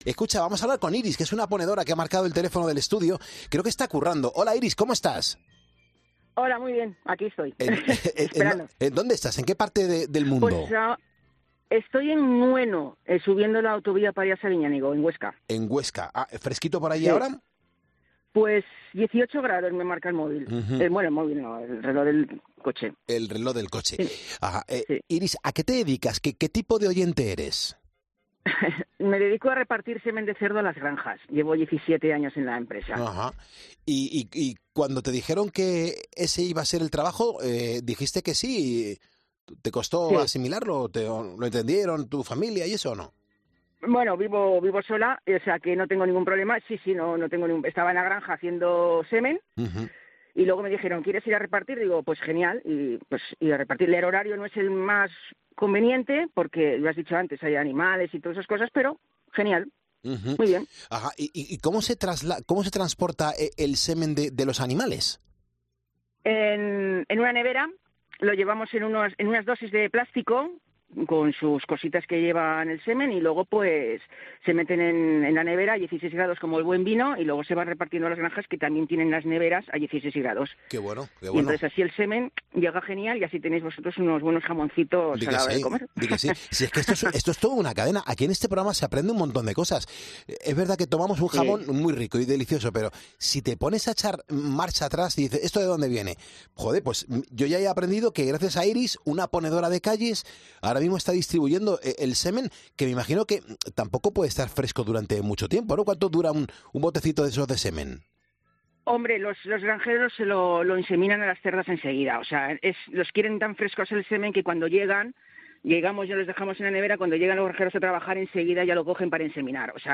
Los trabajadores de la noche son trascendentales, pero el de esta oyente de Poniendo las Calles no deja indiferente a nadie